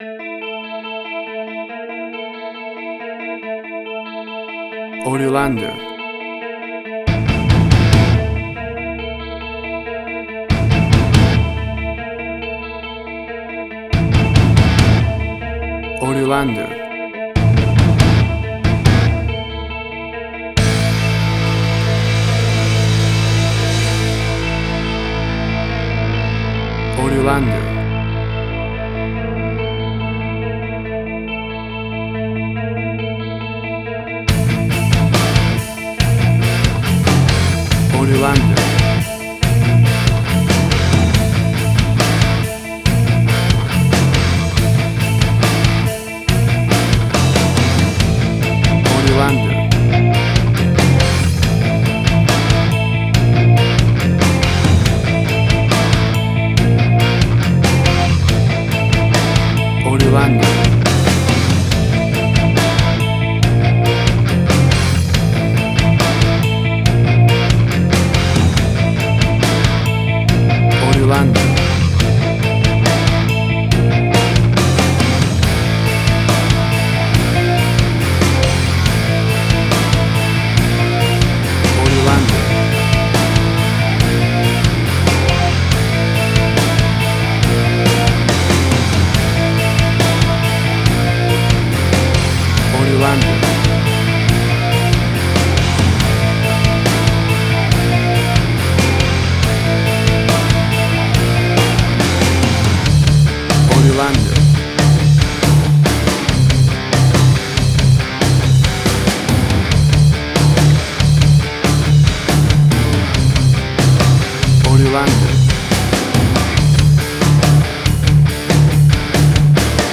Hard Rock
Heavy Metal
Tempo (BPM): 70